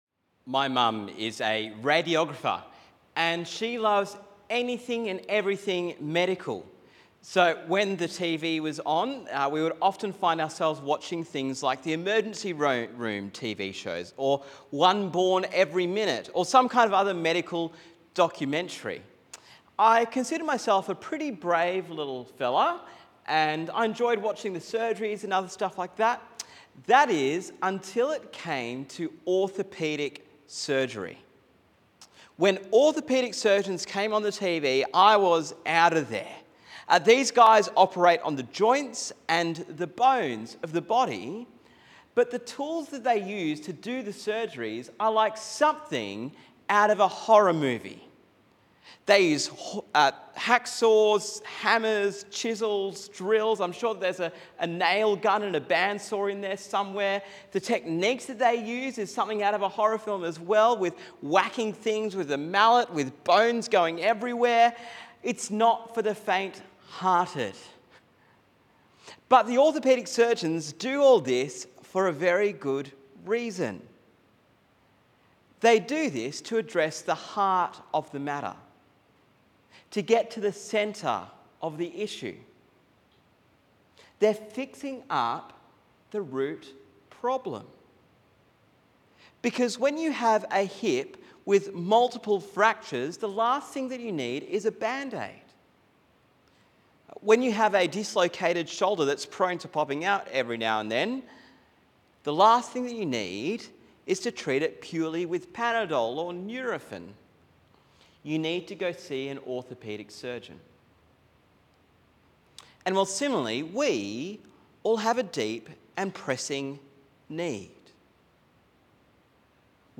Sermon on Mark 2:1-12